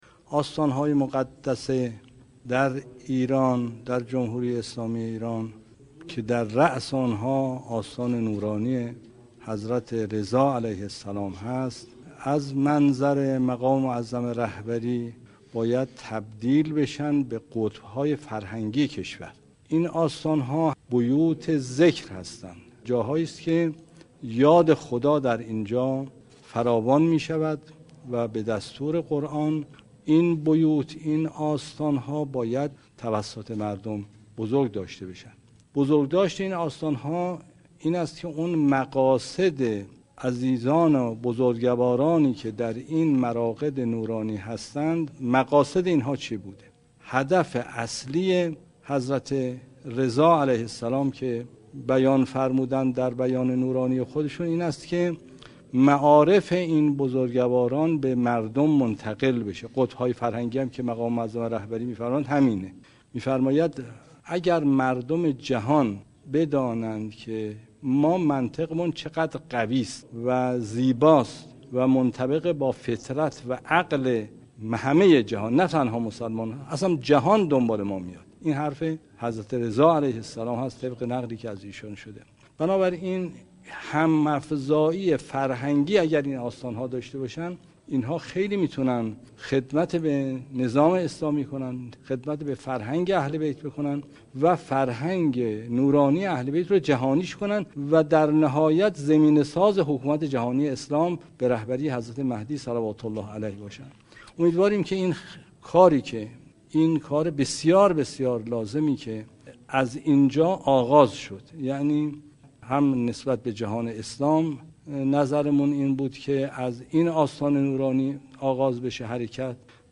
آیت الله محمدی ری شهری در نشست تولیت های آستان های مقدس ایران در حرم مطهر رضوی افزود: امیدواریم حدود 8 هزار آستان مقدسی که در کشور داریم به قطب های فرهنکی تبدیل شوند.